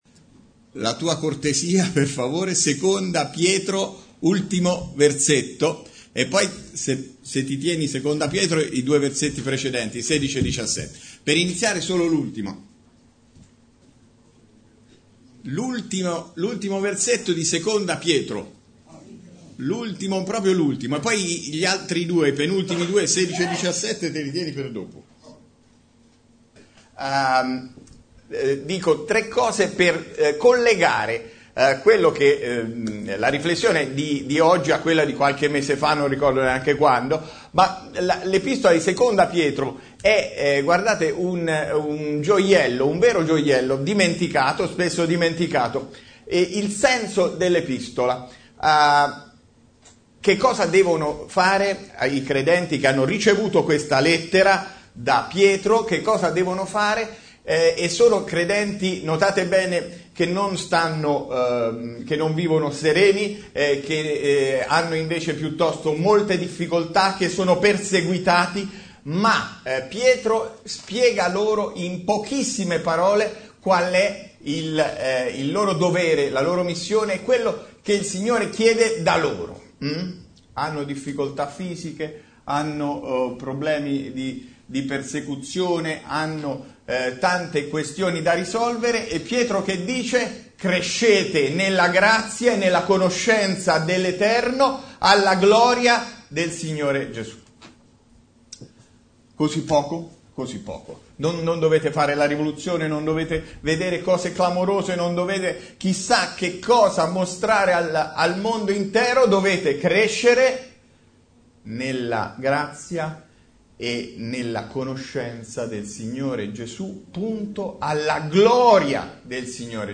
Bible Text: 2 Pietro 3:18 | Preacher